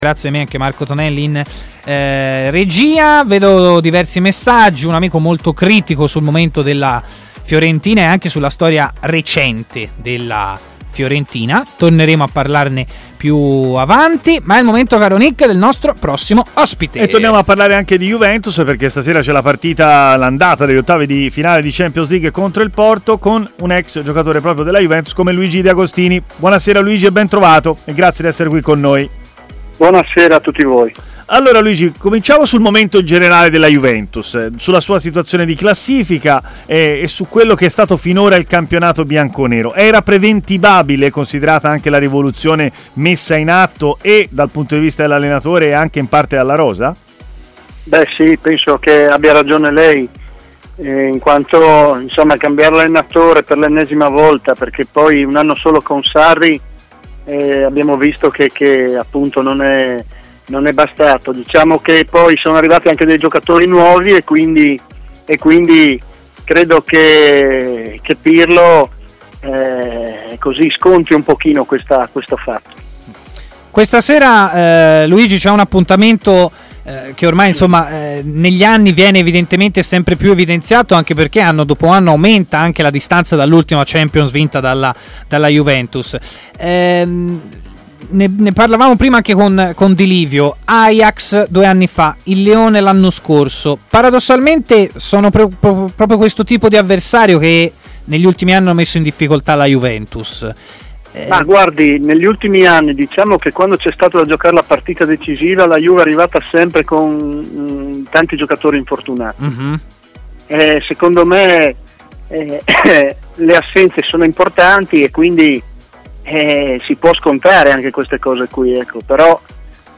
L'ex difensore Luigi De Agostini è intervenuto a Stadio Aperto, trasmissione di TMW Radio